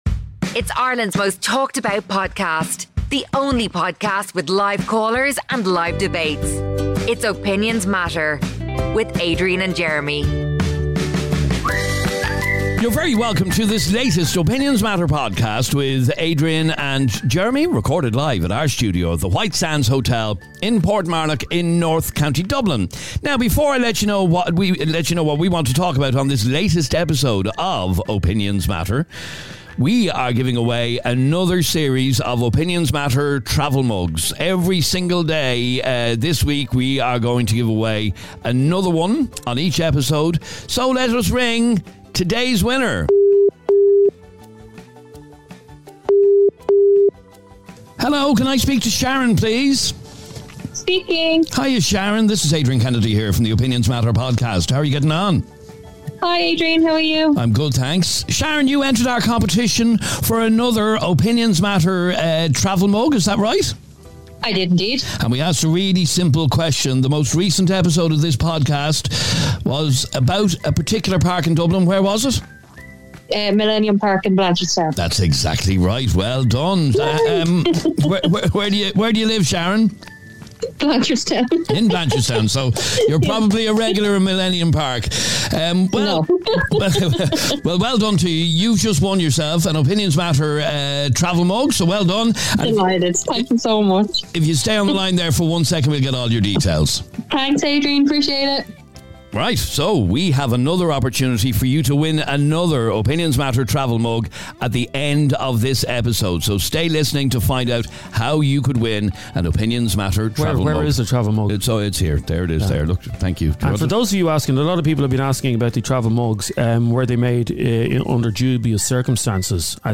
Today on the show we heard from a Dublin mother who says that she has been left in the most impossible situation after her 10-year-old daughters dad got in touch to say he wants to be a part of his daughters life...